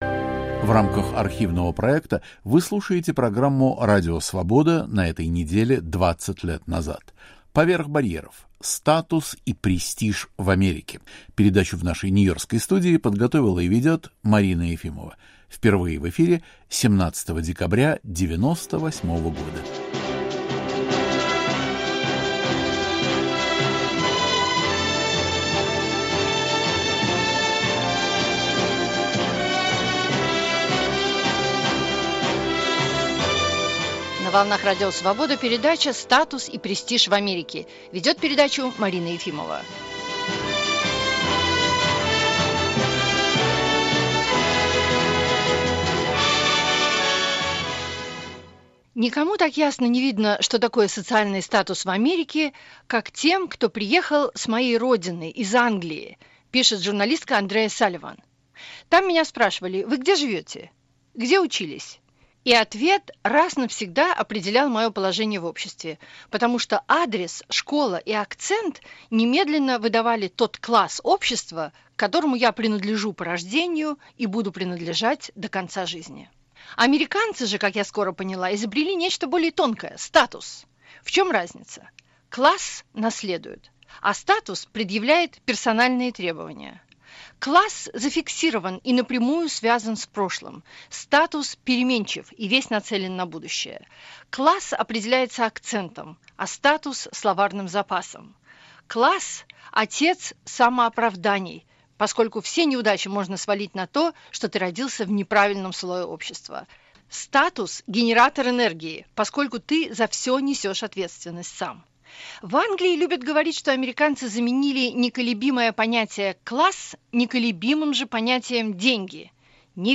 эмигранты с Брайтон-Бич